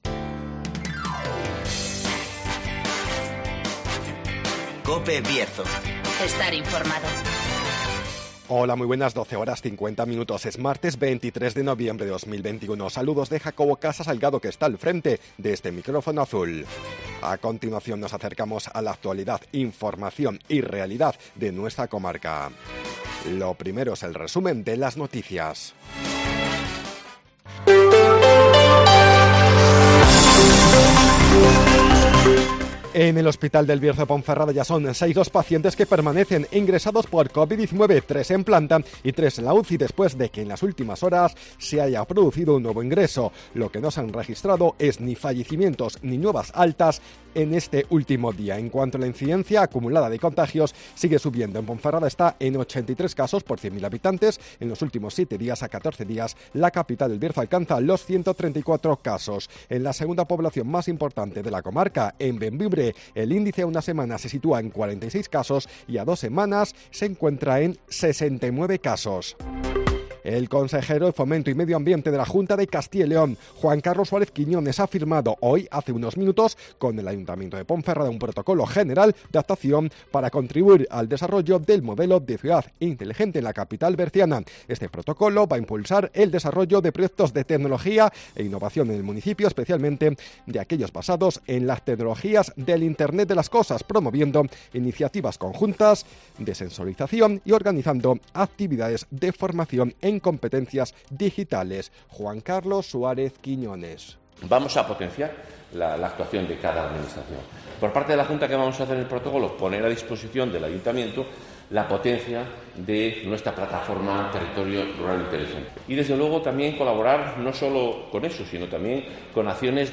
Avance informativo, El Tiempo y Agenda